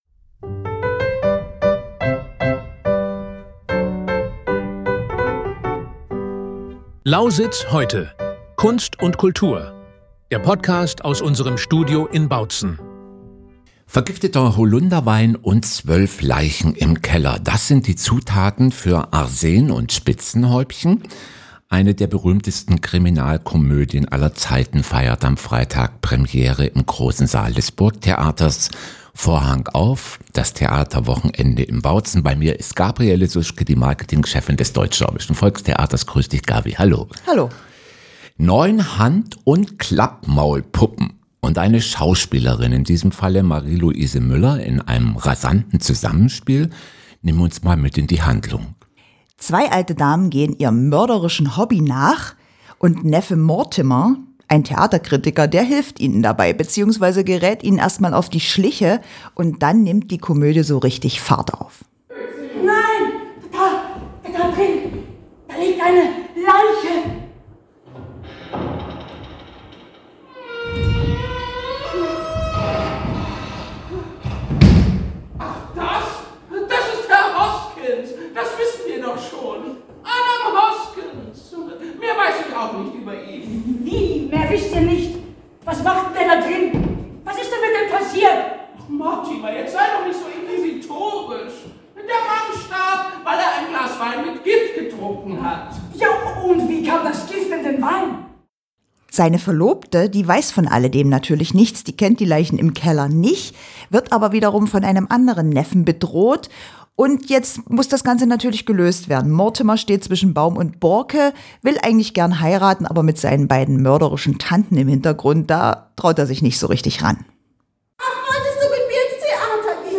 "Vorhang auf" ist Ihr wöchentlicher Theater-Podcast direkt aus